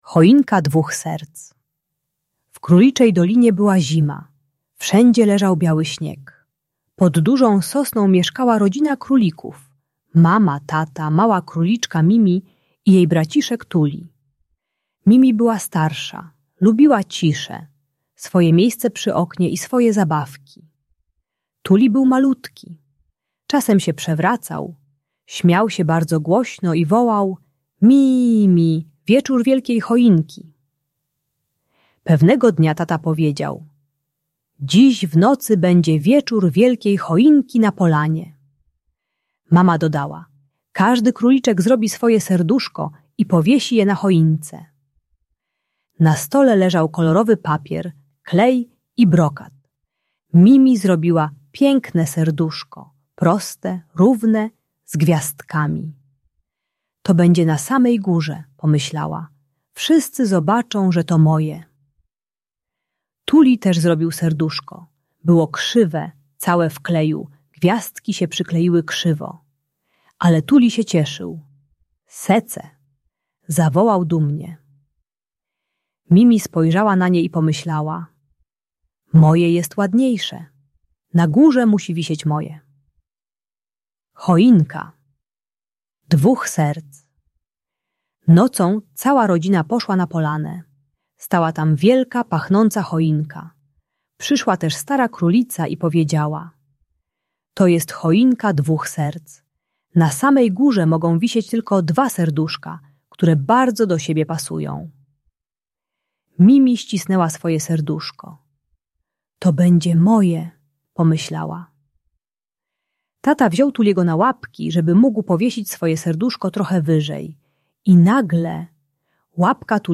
Choinka Dwóch Serc - Rodzeństwo | Audiobajka